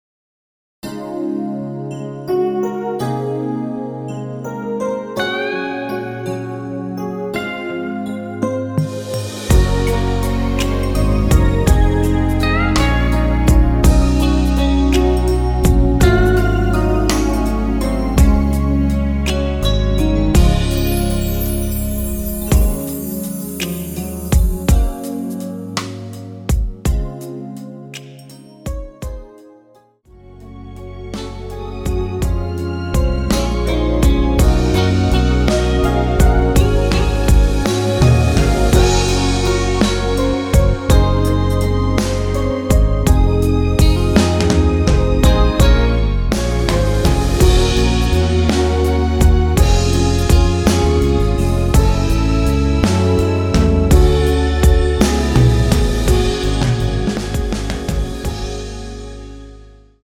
원키에서(-1)내린 (2절 삭제) MR입니다.(미리듣기 참조)
Db
앞부분30초, 뒷부분30초씩 편집해서 올려 드리고 있습니다.
중간에 음이 끈어지고 다시 나오는 이유는